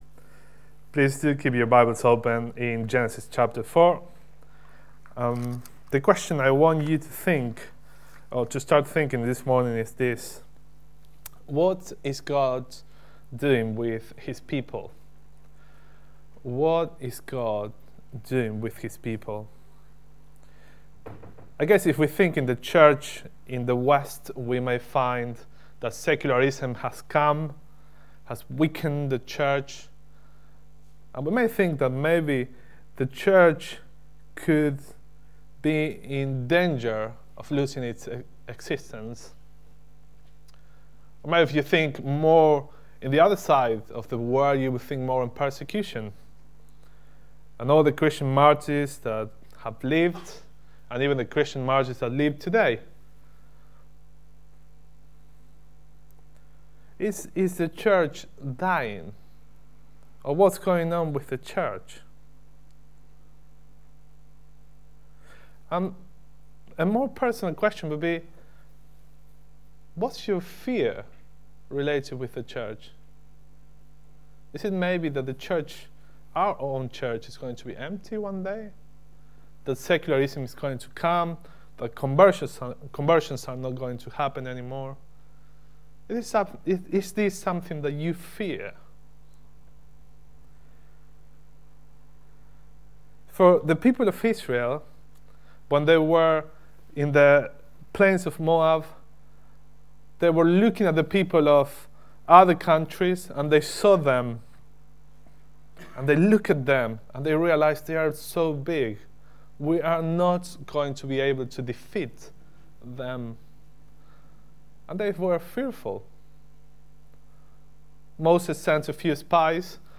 Single Sermon | Hope Church Goldington